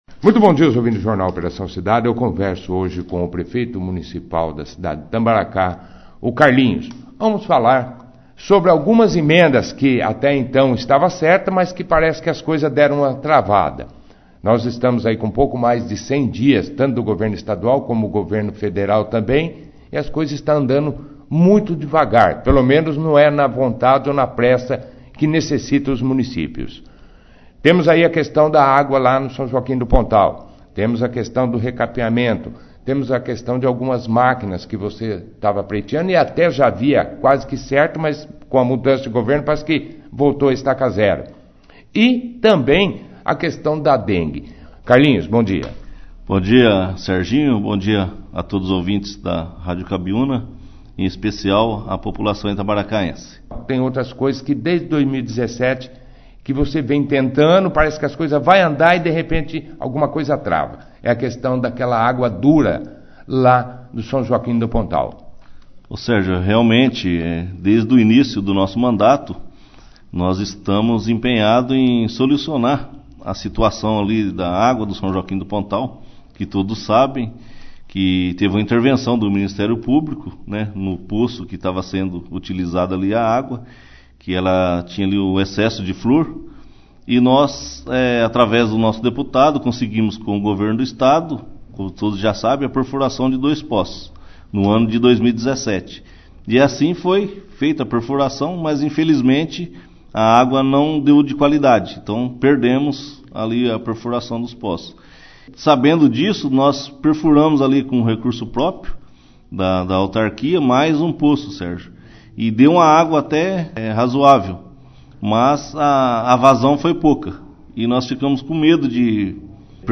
O Prefeito de Itambaracá Carlos Cesar de Carvalho o “Carlinhos” foi o destaque do Jornal Operação Cidade deste sábado (20).